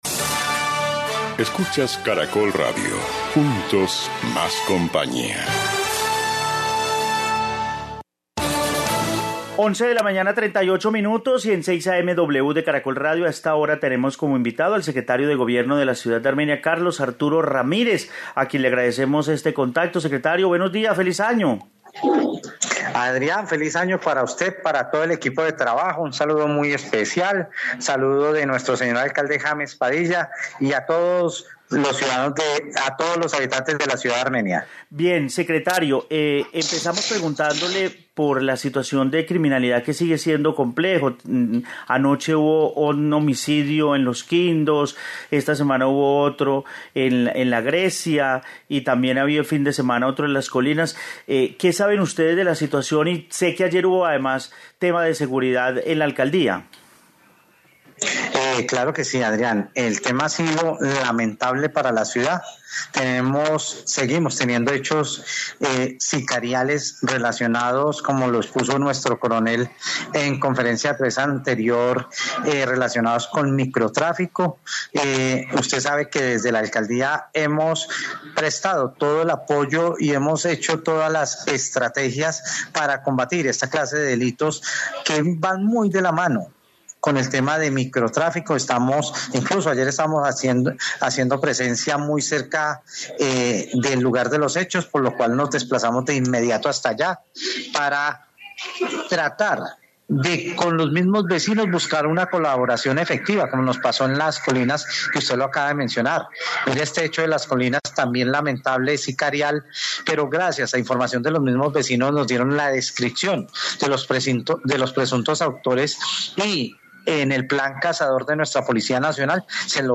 Carlos Arturo Ramírez, secretario de gobierno de Armenia